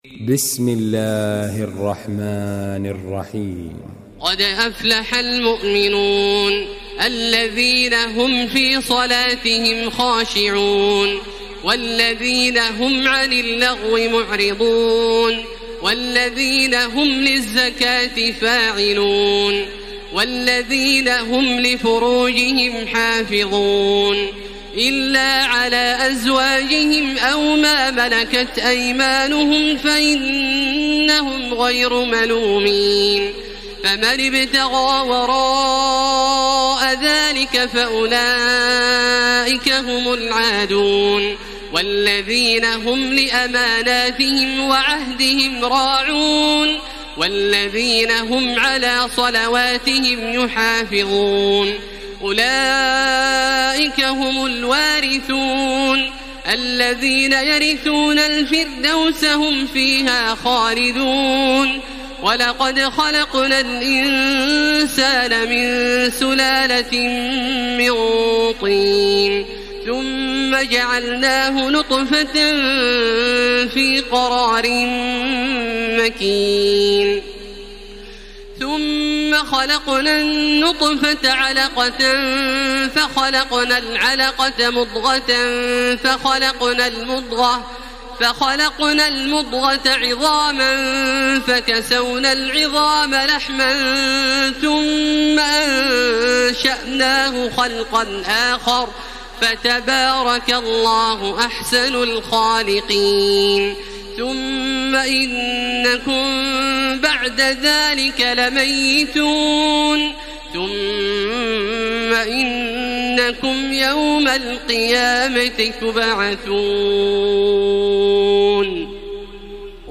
تراويح الليلة السابعة عشر رمضان 1433هـ سورتي المؤمنون و النور (1-20) Taraweeh 17 st night Ramadan 1433H from Surah Al-Muminoon and An-Noor > تراويح الحرم المكي عام 1433 🕋 > التراويح - تلاوات الحرمين